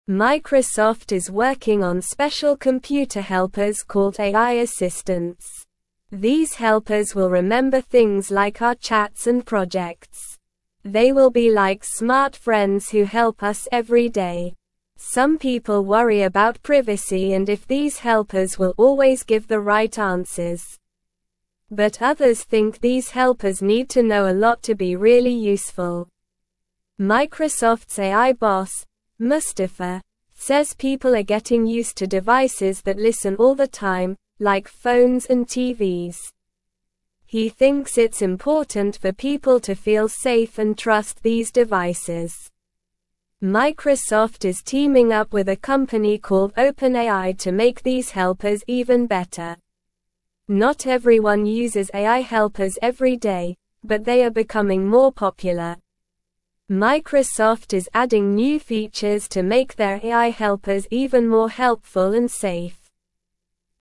Slow
English-Newsroom-Beginner-SLOW-Reading-Microsoft-is-Making-Smart-Friends-to-Help-You.mp3